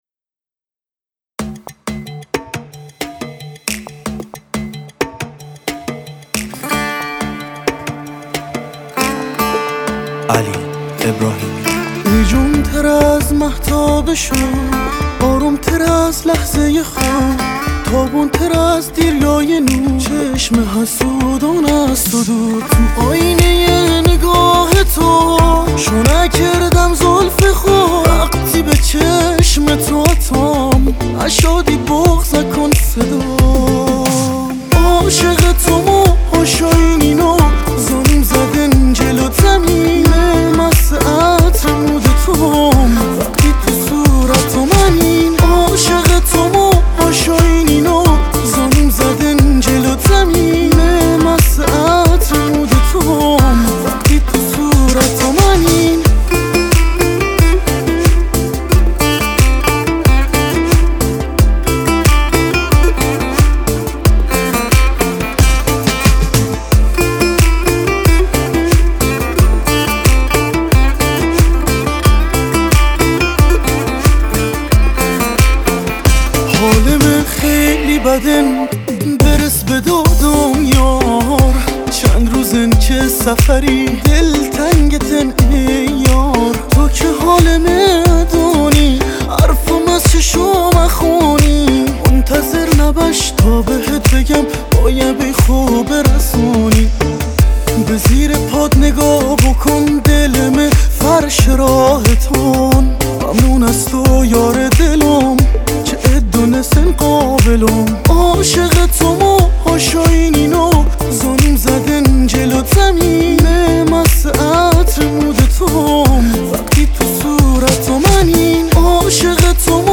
کیبورد
سه تار